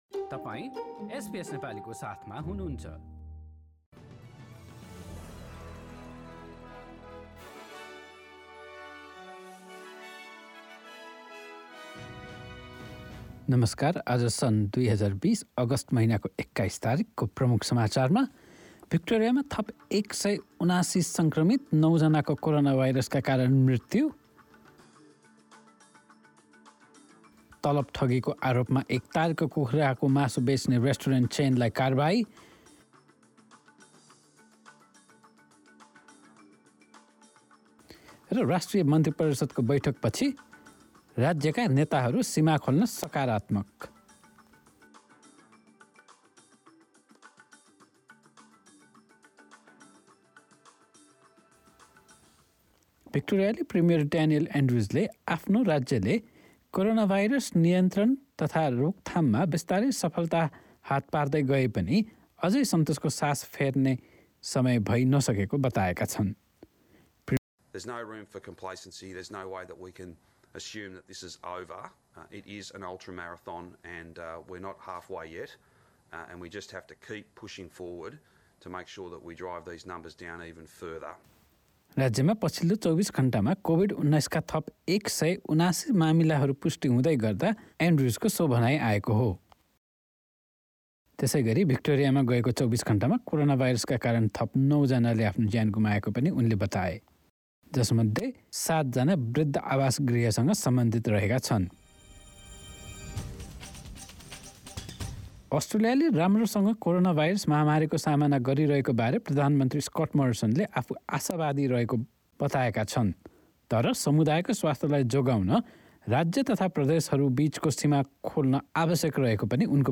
एसबीएस नेपाली अस्ट्रेलिया समाचार: शुक्रवार २१ अगस्ट २०२०